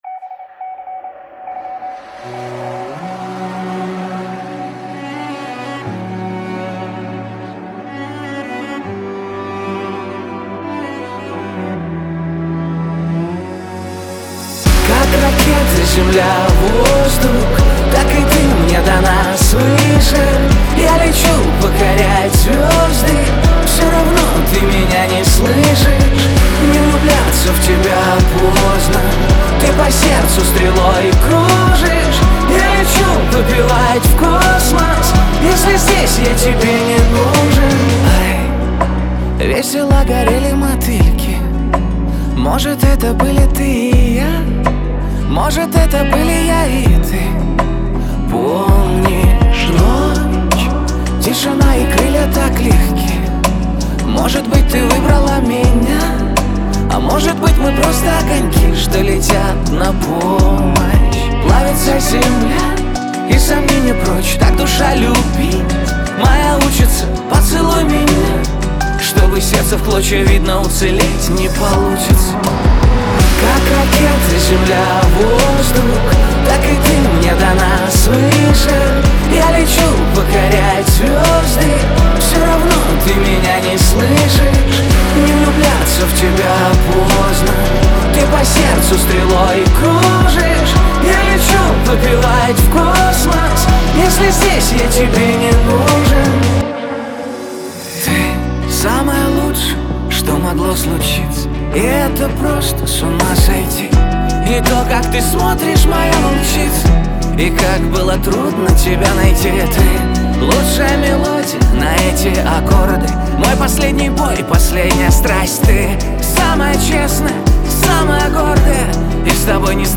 pop
эстрада
диско